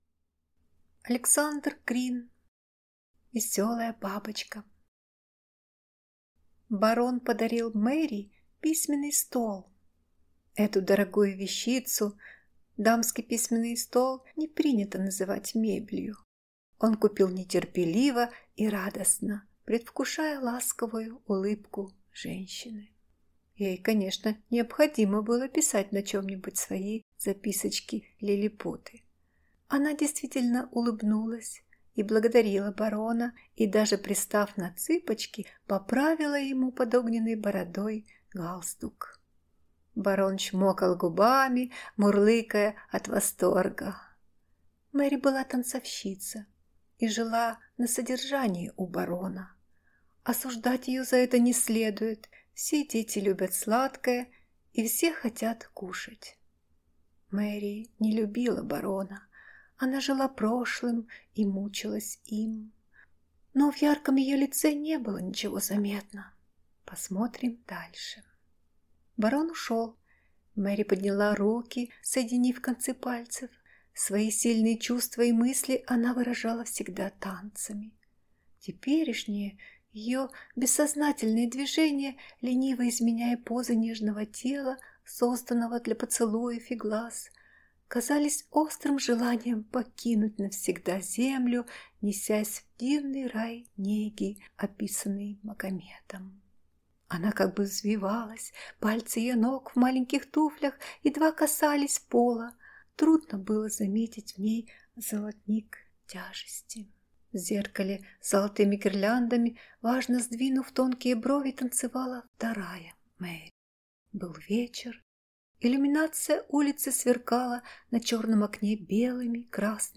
Аудиокнига Веселая бабочка | Библиотека аудиокниг